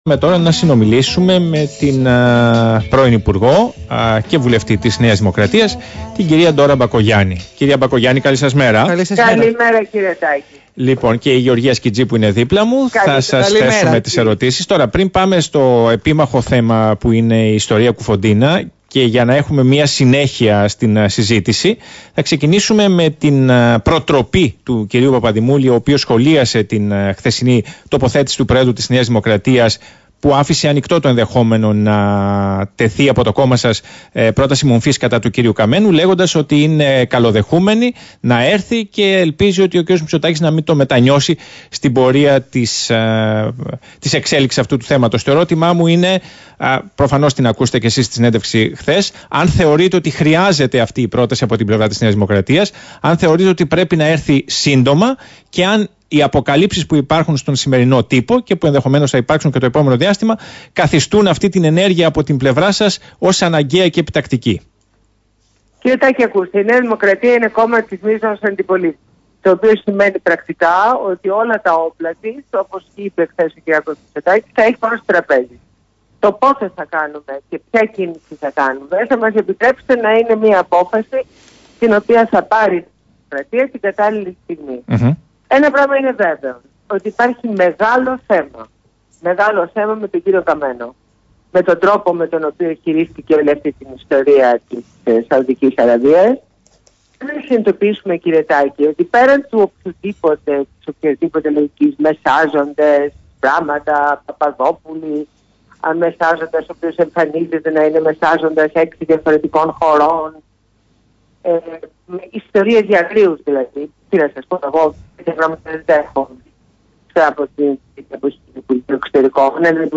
Συνέντευξη στο ραδιόφωνο Παραπολιτικά